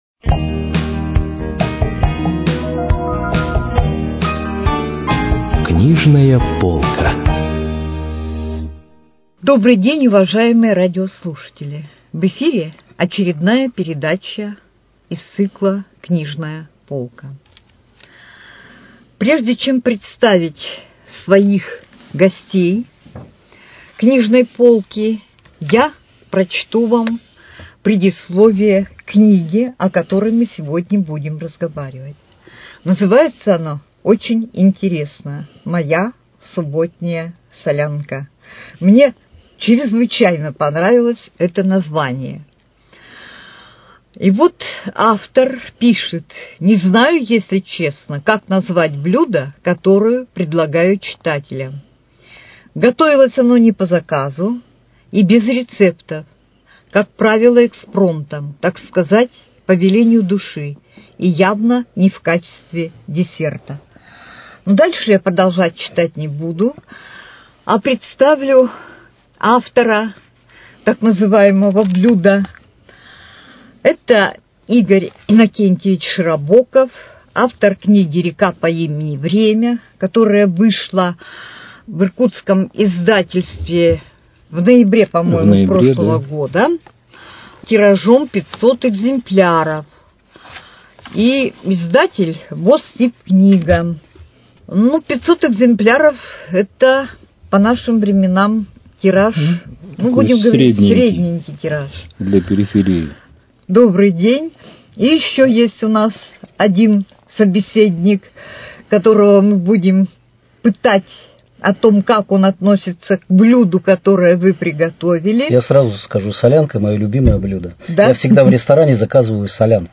Книжная полка: Беседа
Передача из цикла «Книжная полка».